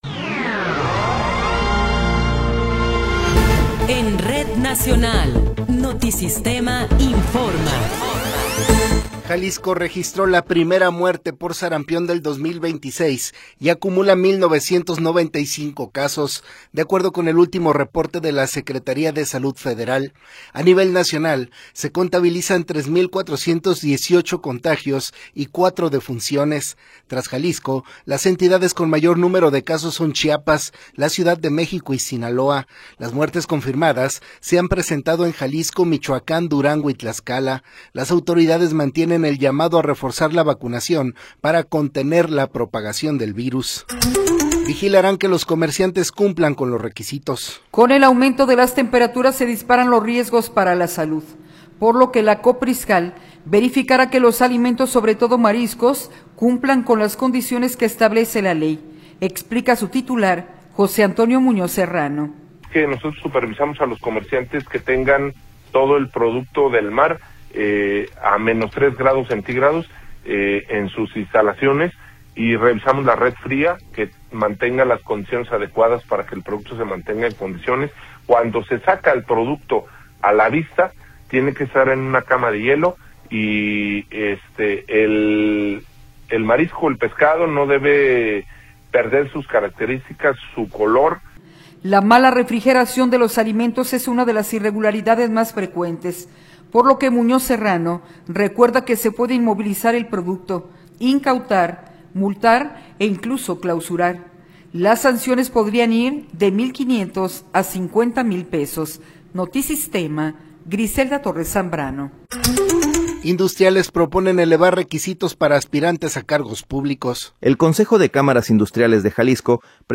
Noticiero 12 hrs. – 17 de Febrero de 2026